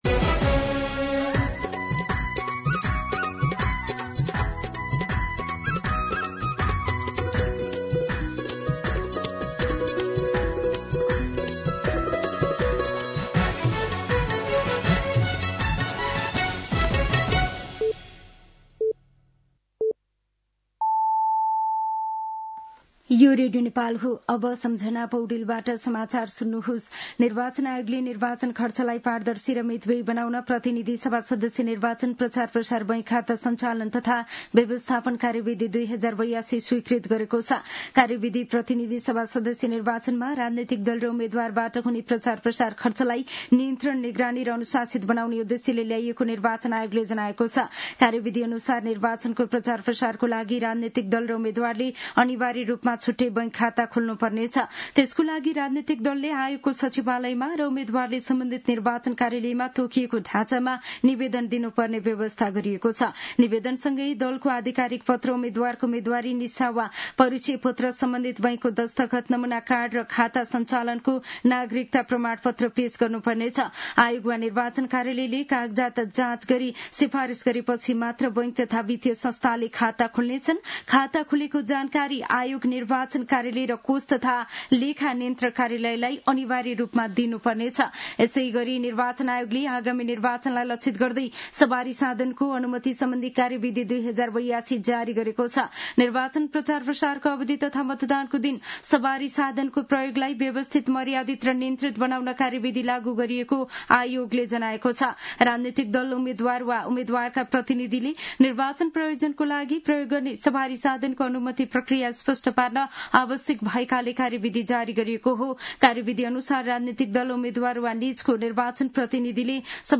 मध्यान्ह १२ बजेको नेपाली समाचार : २२ माघ , २०८२